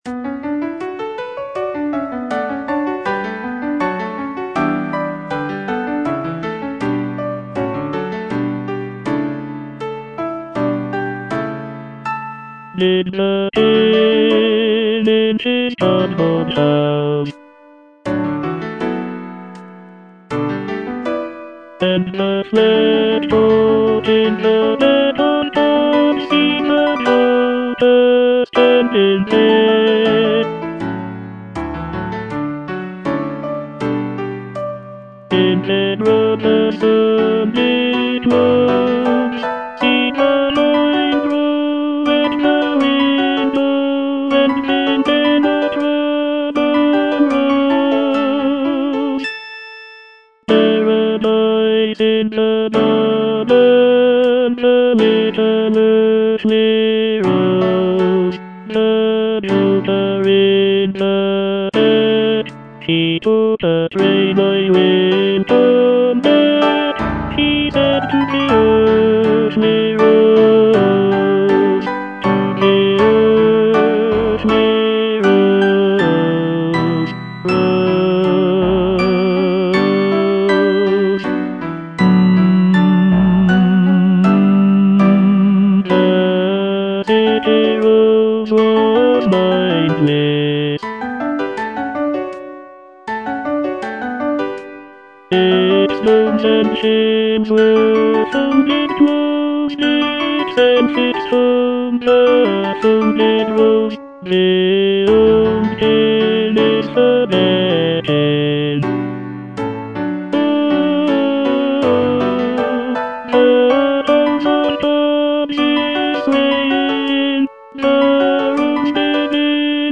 Tenor II (Voice with metronome)
is a choral work